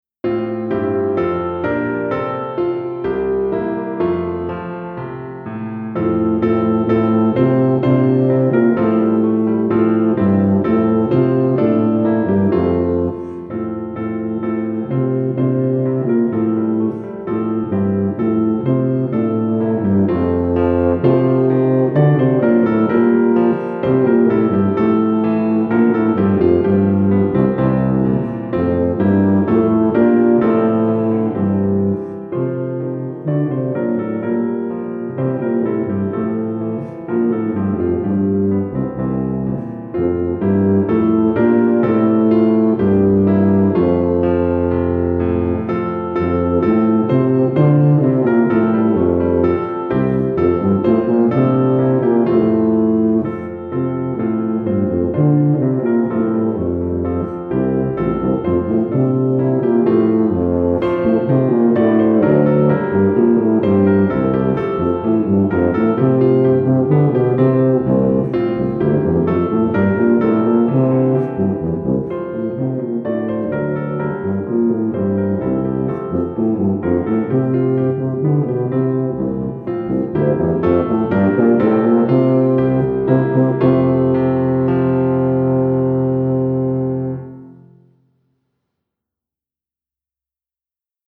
Tuba et Piano